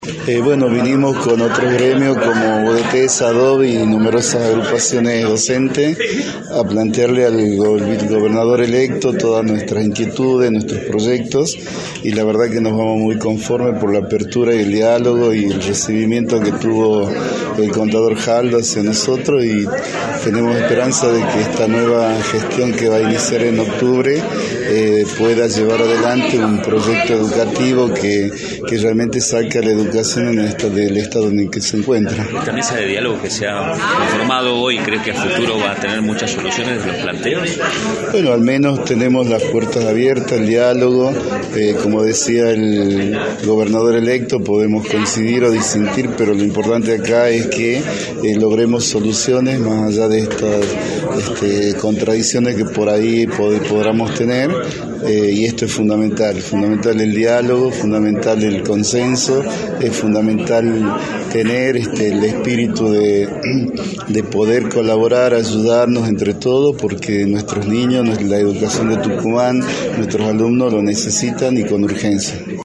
en entrevista para Radio del Plata Tucumán.